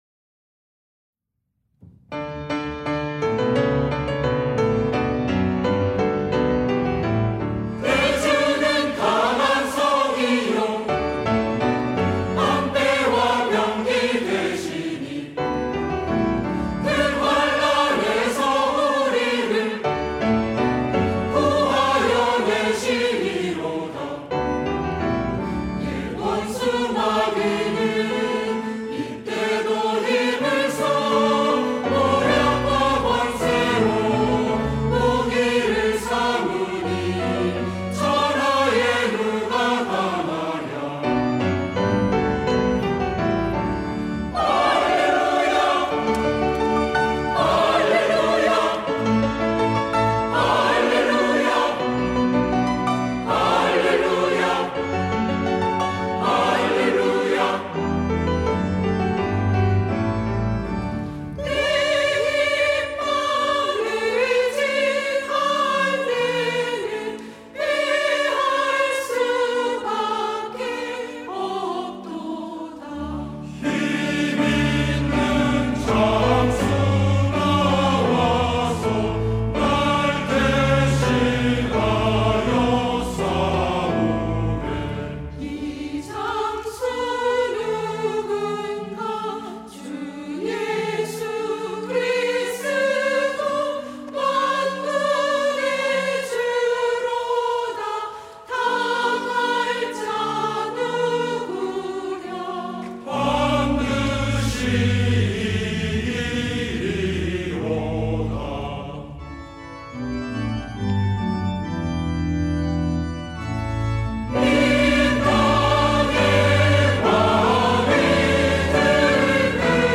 시온(주일1부) - 내 주는 강한 성이요
찬양대